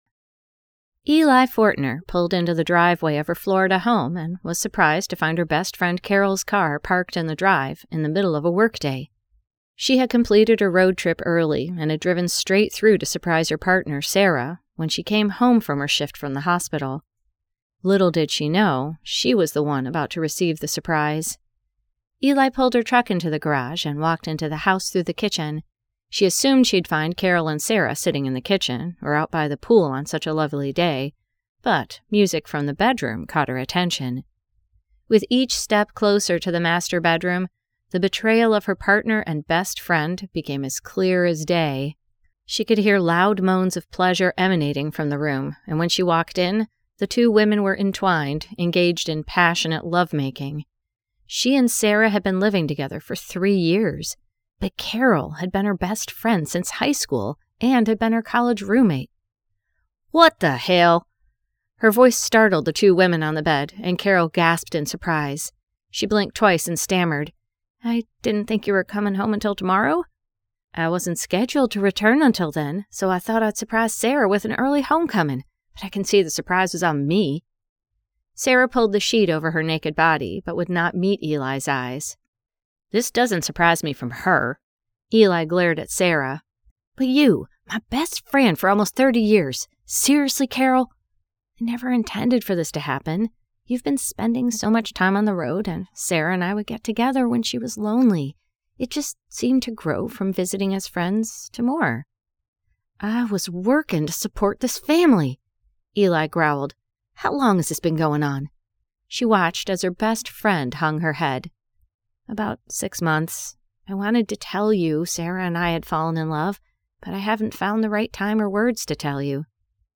The Mountain Whispers Cast Iron Farm Series, Book 1 [Audiobook]